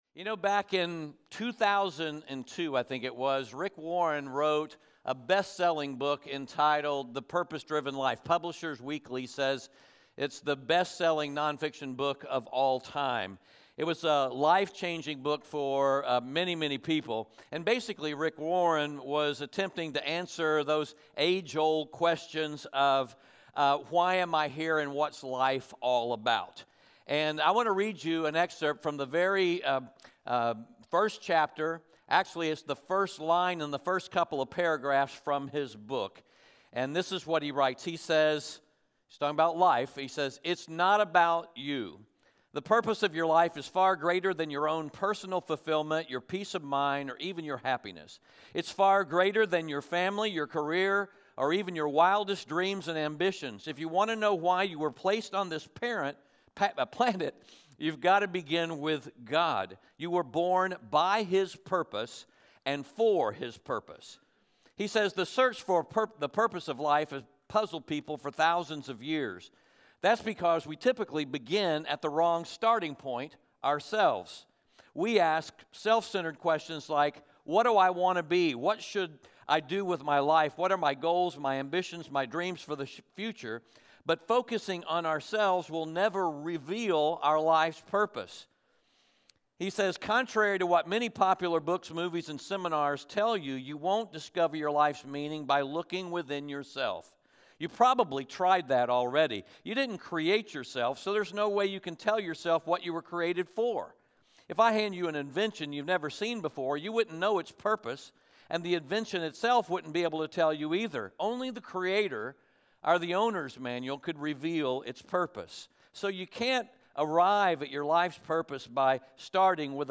Romans 15:14-33 Audio Sermon Notes (PDF) Ask a Question Back in 2002, Rick Warren wrote a book entitled, The Purpose-Driven Life.